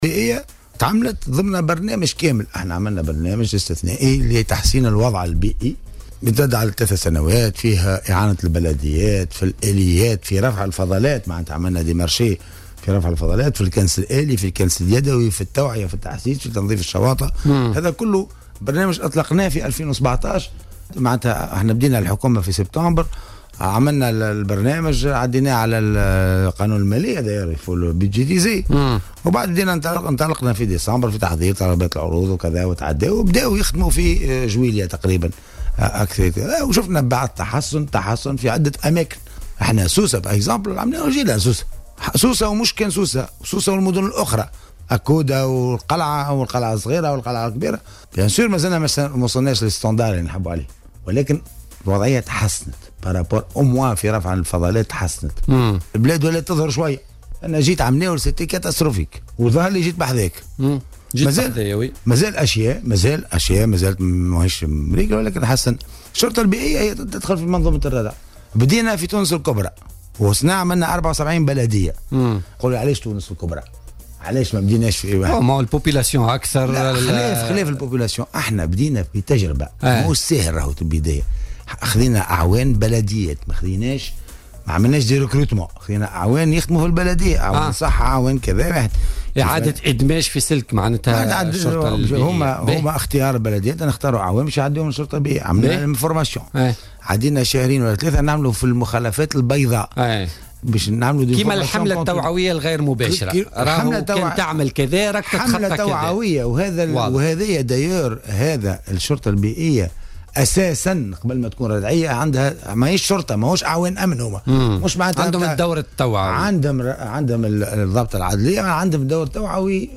وتابع ضيف "بوليتيكا" أن هذه الشرطة بدأت بـ 74 بلدية فيما سيتمّ اضافة 46 بلدية أخرى العام المقبل.